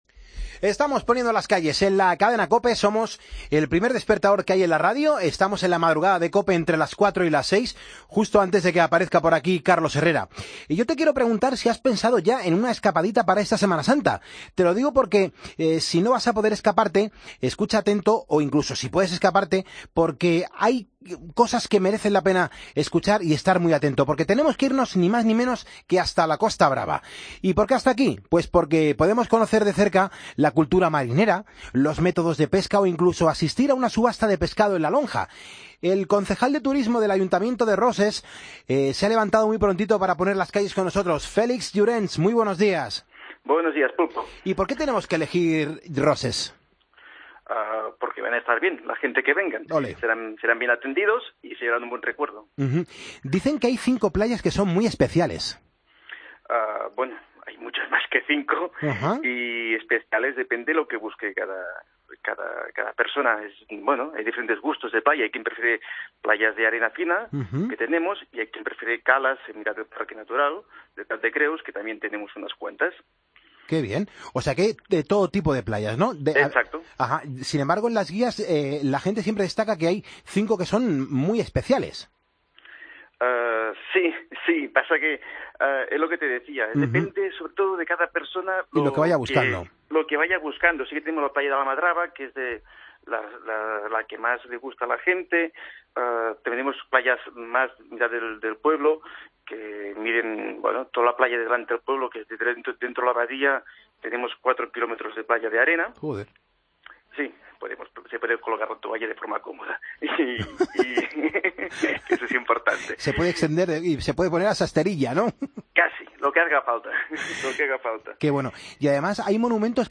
AUDIO: El concejal de Turismo de Roses, Félix Llorens nos cuenta qué tiene de especial.